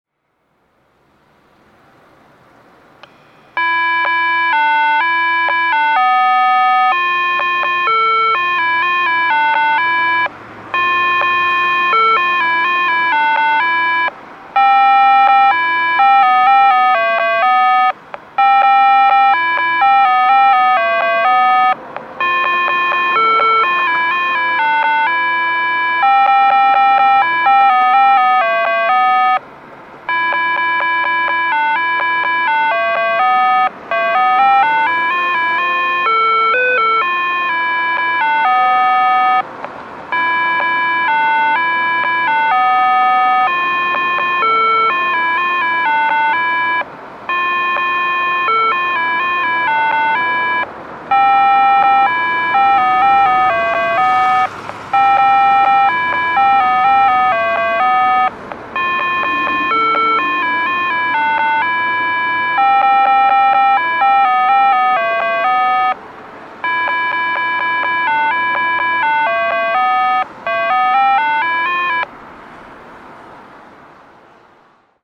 交通信号オンライン｜音響信号を録る旅｜大分県の音響信号｜[大分中央:0057]盲学校南
盲学校南(大分県大分市)の音響信号を紹介しています。